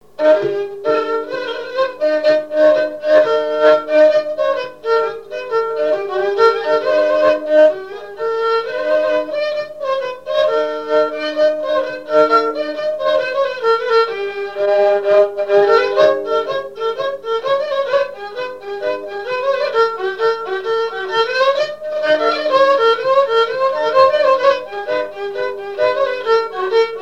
Mémoires et Patrimoines vivants - RaddO est une base de données d'archives iconographiques et sonores.
danse-jeu : danse du balais
Pièce musicale inédite